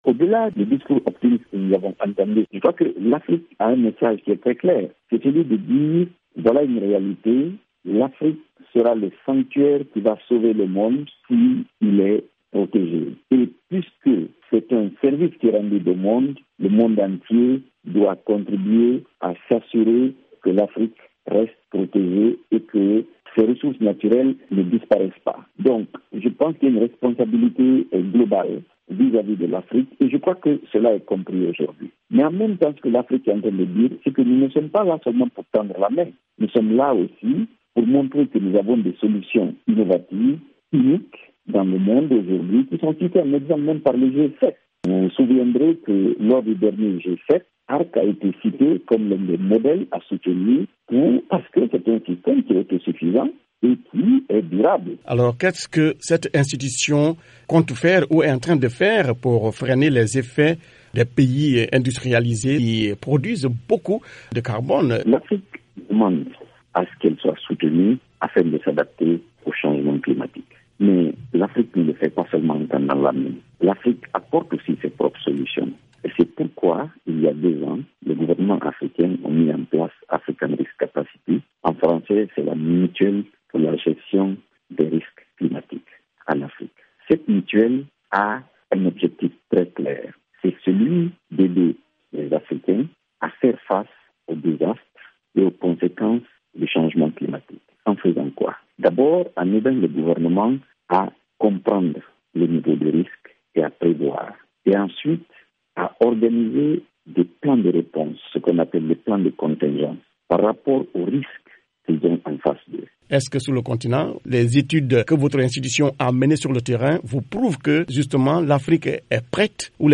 quelques éléments de réponse avec